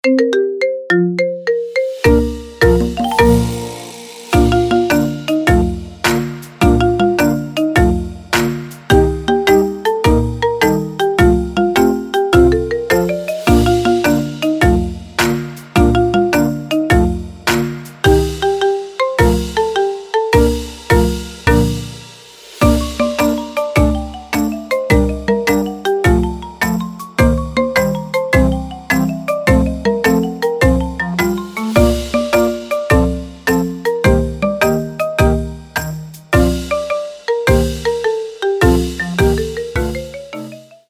Рингтоны без слов , Рингтоны ремиксы
Маримба